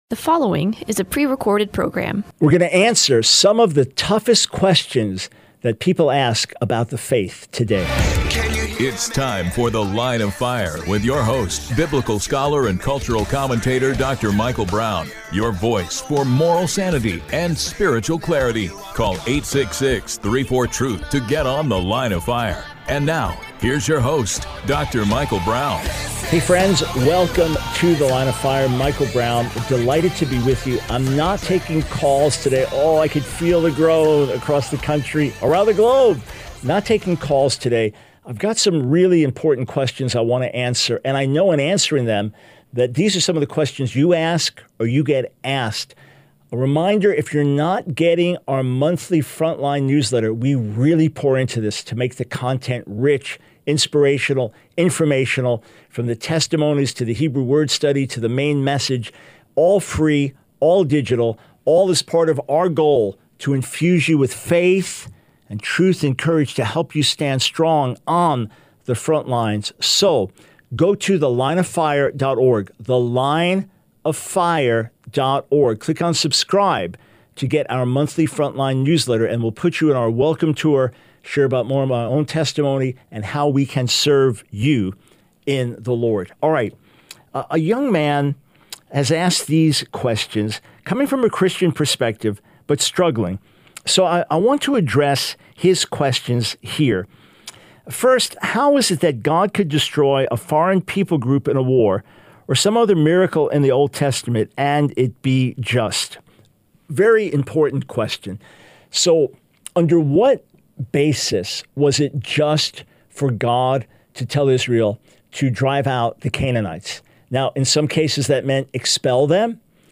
The Line of Fire Radio Broadcast for 05/24/24.